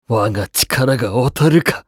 男性
厨二病ボイス～戦闘ボイス～